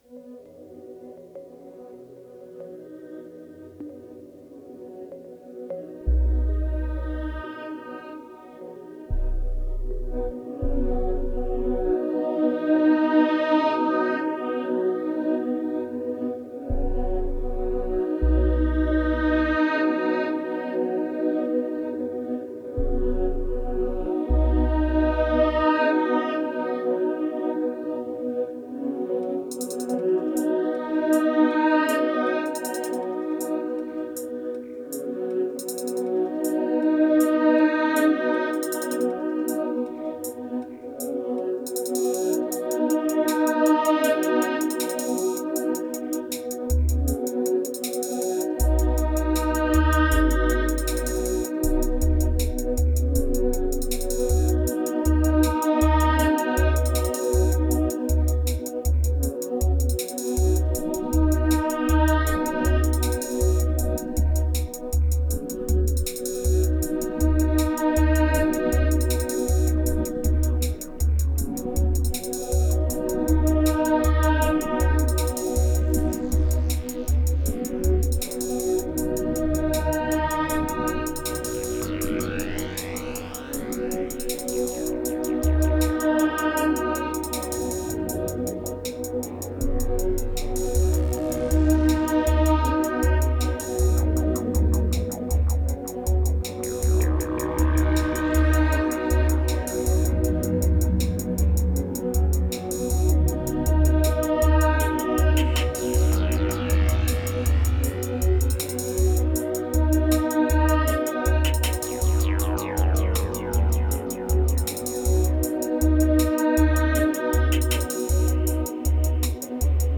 2604📈 - -25%🤔 - 79BPM🔊 - 2017-09-11📅 - -652🌟